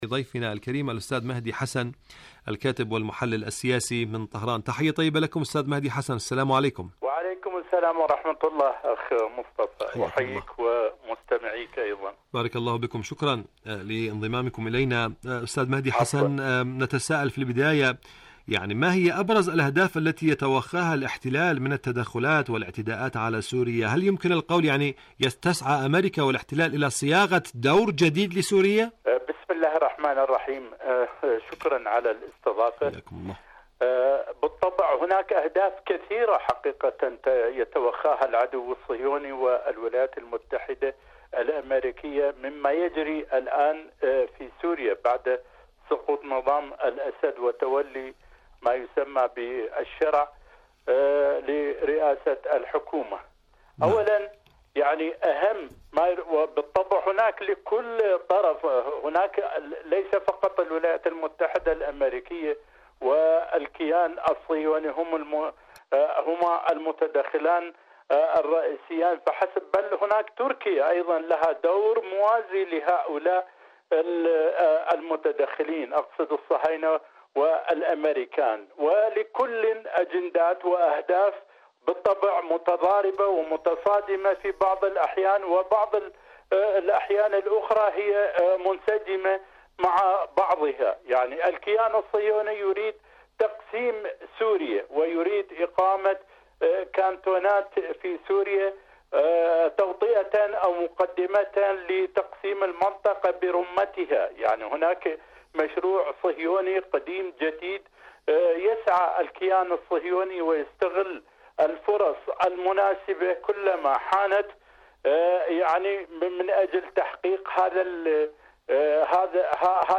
مقابلات برامج إذاعة طهران العربية برنامج حدث وحوار مقابلات إذاعية محاولات الاحتلال صياغة دور لسورية الجديدة شاركوا هذا الخبر مع أصدقائكم ذات صلة آليات إيران للتعامل مع الوكالة الدولية للطاقة الذرية..